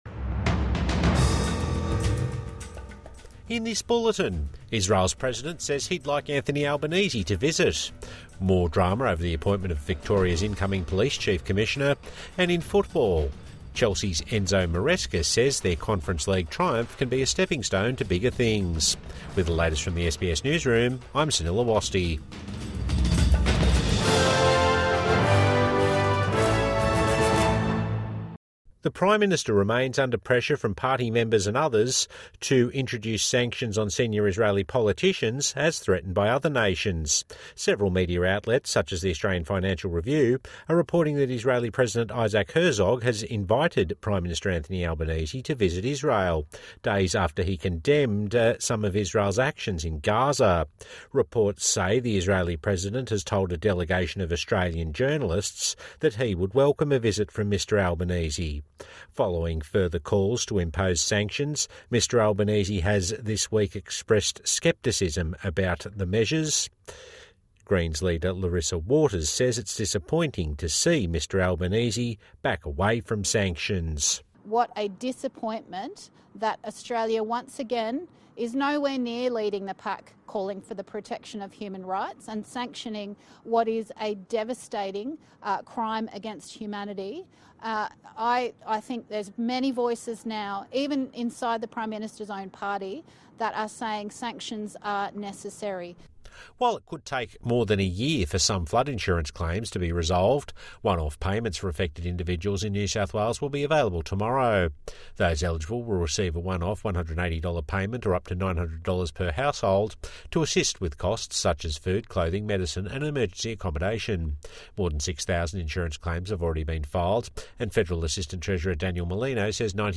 Herzog wants Albanese to visit Israel | Evening News Bulletin 29 May 2025